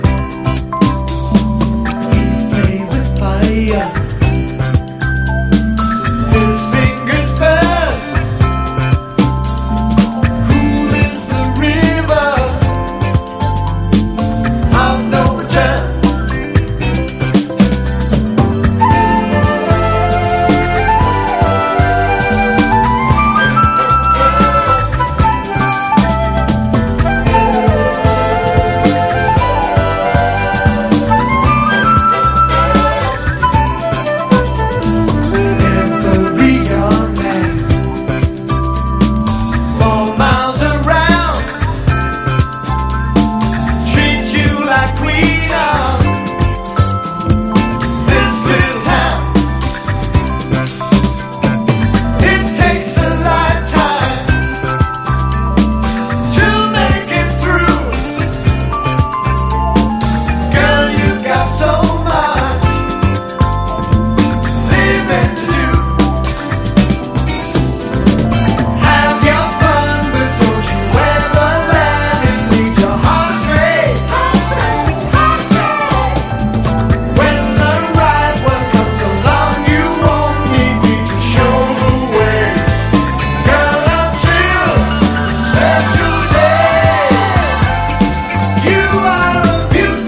ROCK / S.S.W./A.O.R. / DRUM BREAK / FREE SOUL